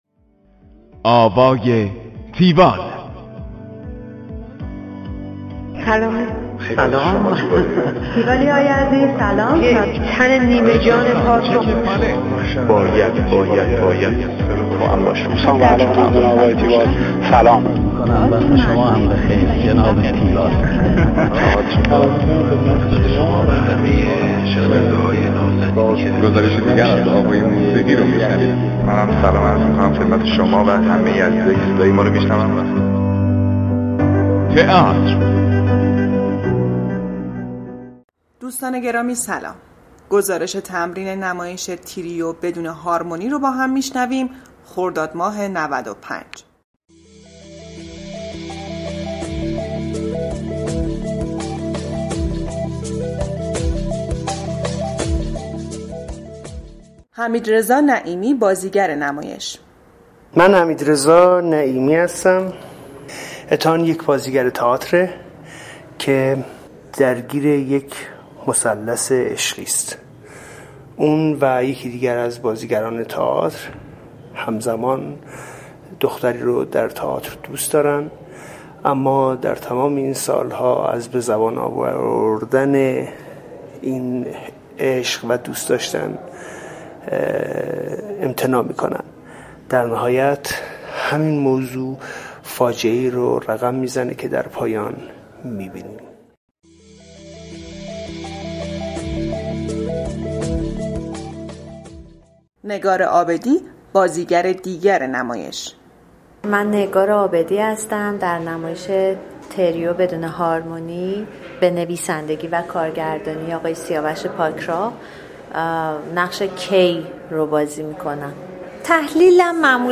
گزارش آوای تیوال از نمایش تریو بدون هارمونی